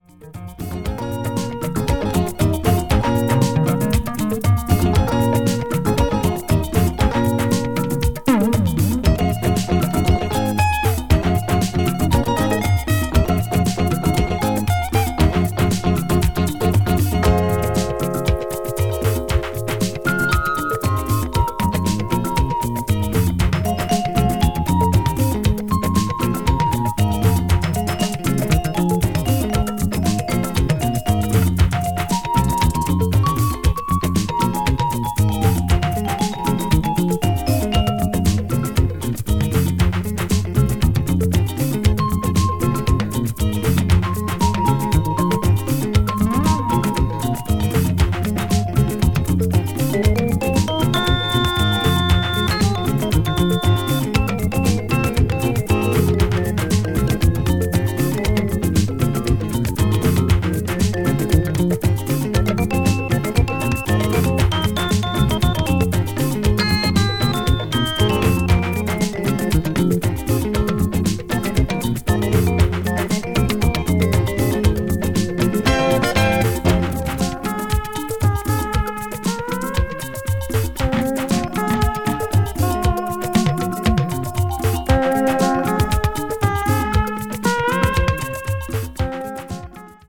is a prime example of Nigerian juju music.